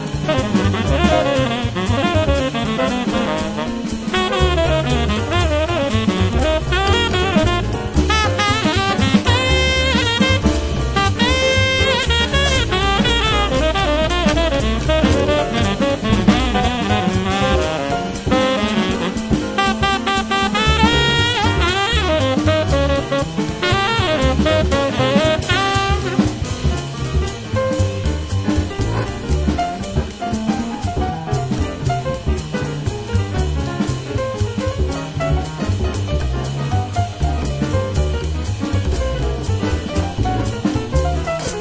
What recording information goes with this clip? Recorded Red Gable Studio, London 31st August 2006